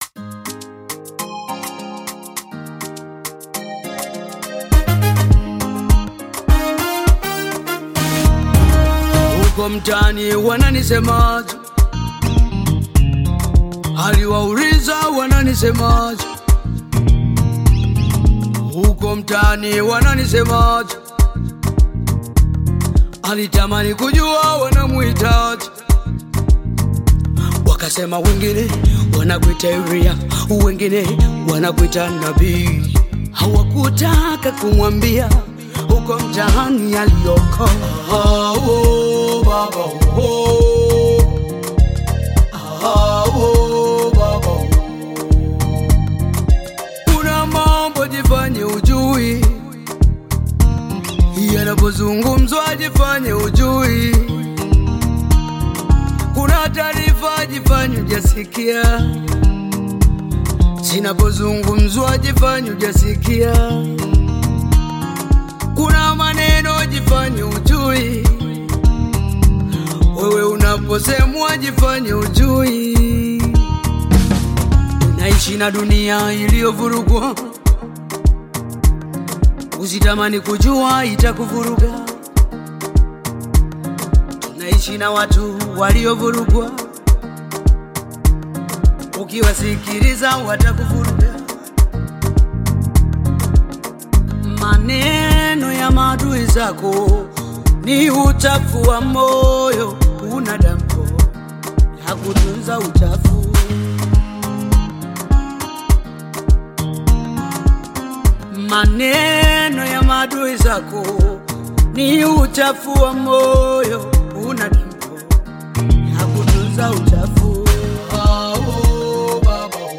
Gospel music track
Tanzanian gospel artist, singer, and songwriter
Gospel song